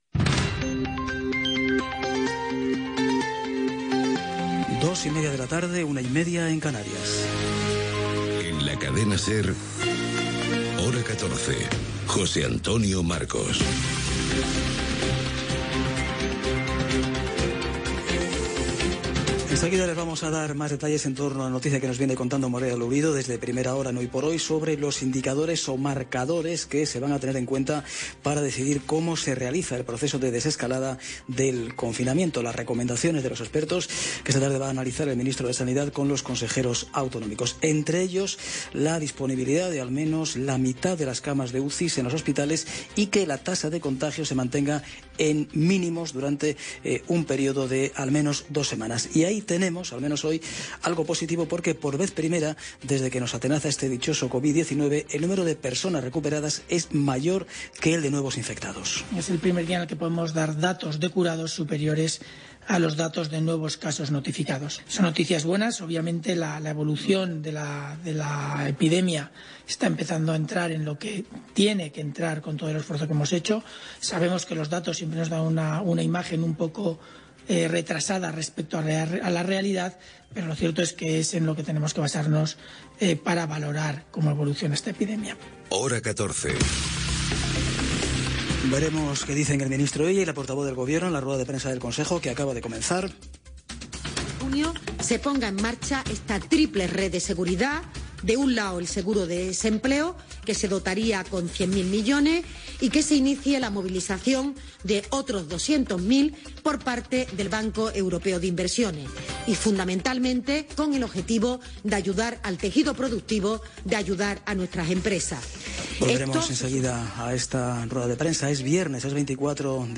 Hora, careta, informació sobre la desescalada del confinament degut a la pandèmia de la Covid 19. Opinió de Miguel Ángel Aguilar, equip, condemna a membres del Betis i Osasuna per suborns en un partit de futbol, el temps.
Informatiu